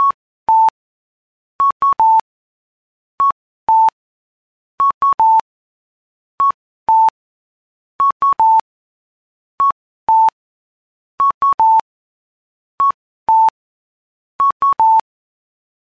音響式信号機の色々
ちなみに、カッコーは
「カッコー」と「カカッコー」 です。